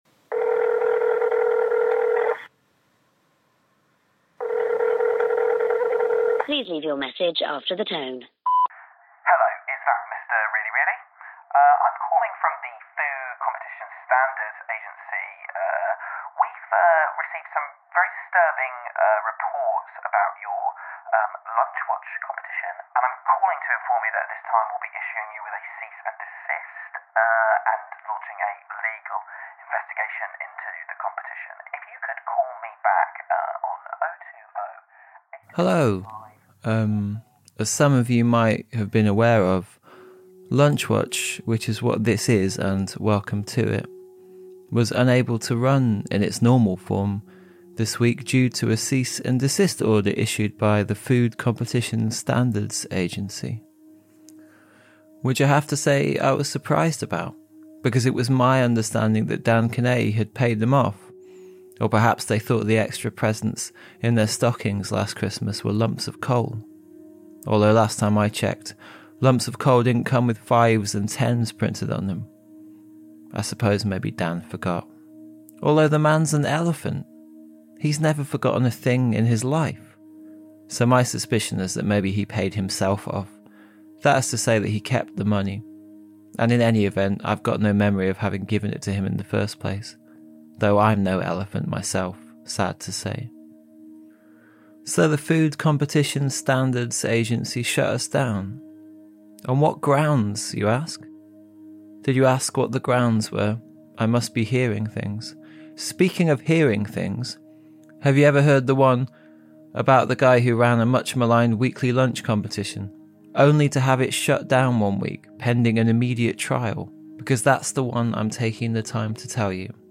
With original music
The coin flip was performed